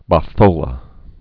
(bŏf-ōlə)